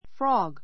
frog 小 A1 frɑ́ɡ ふ ラ グ 名詞 動物 カエル Frogs are croaking.